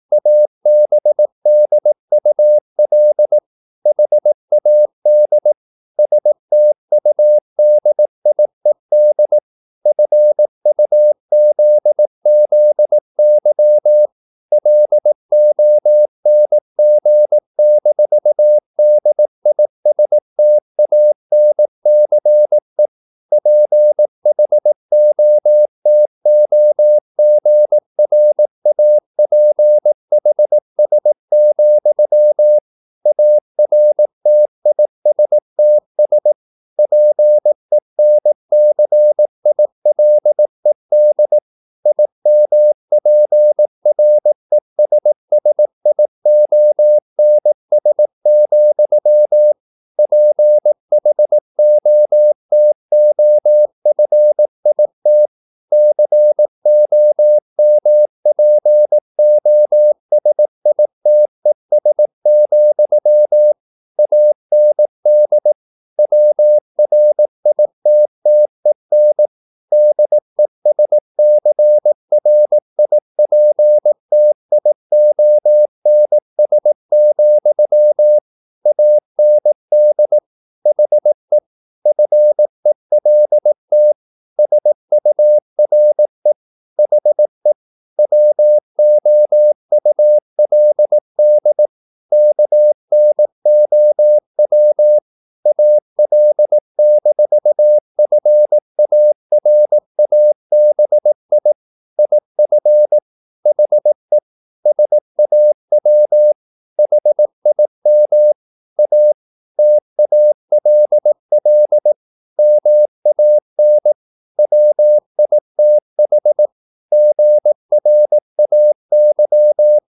15 - 19 wpm | CW med Gnister
Romanen Never af Ken Follet på engelsk. LYD FIL: Never_0018wpm.mp3 Hastighed: 15 - 19 wpm Sprog: Engelsk Rate: Select rating Give Never 18wpm 1/5 Give Never 18wpm 2/5 Give Never 18wpm 3/5 Give Never 18wpm 4/5 Give Never 18wpm 5/5 No votes yet
Never_0018wpm.mp3